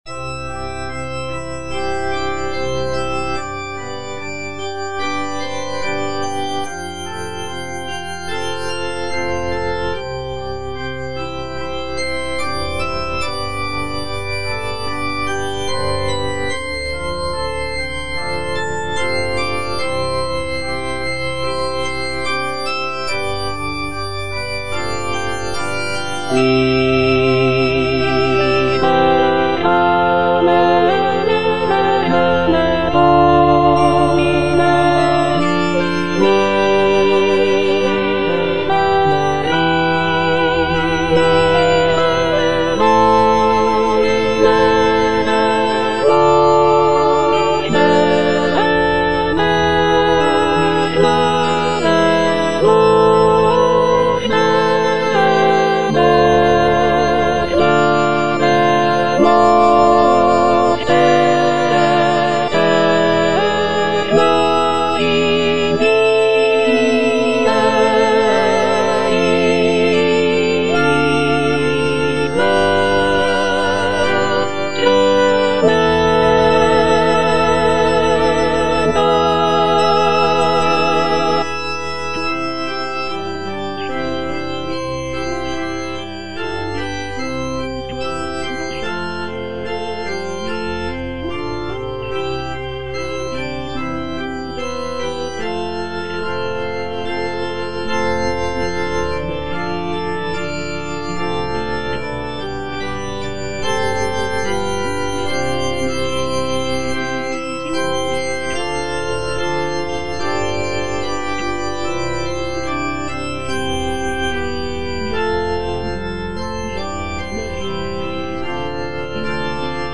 Alto (Emphasised voice and other voices) Ads stop
is a sacred choral work rooted in his Christian faith.